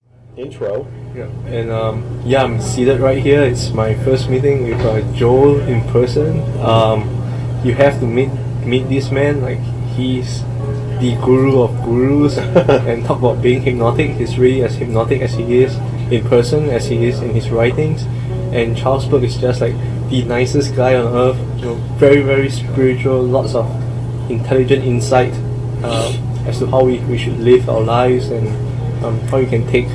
A perfect clear quality audio ebook.
A useful audio book in your business and day to day life.